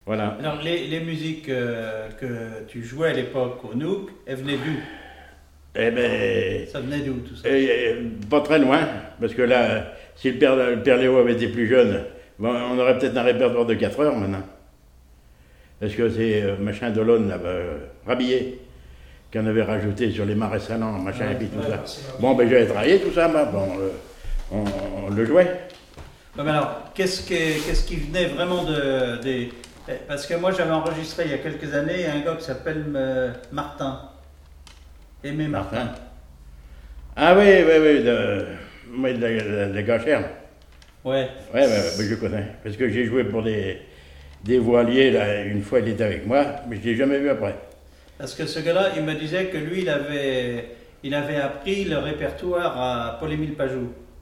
Témoignage sur la musique et des airs issus du Nouc'h